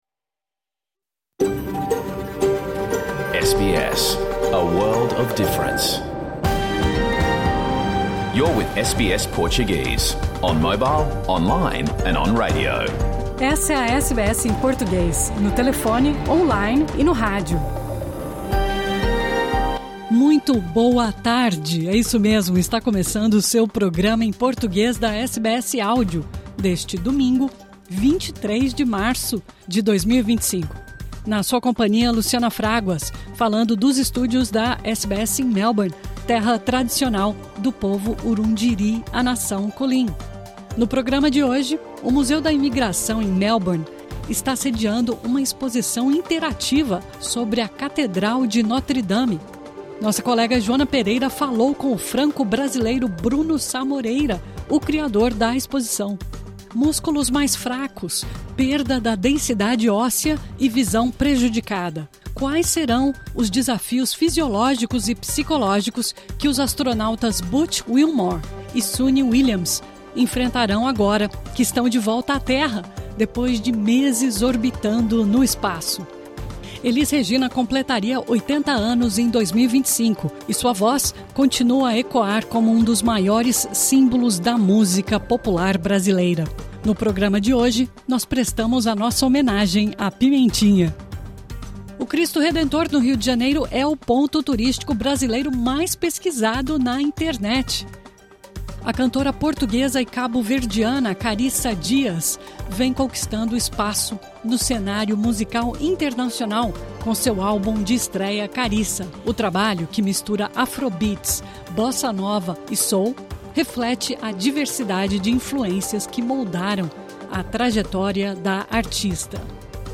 Programa ao vivo | Domingo 23 de março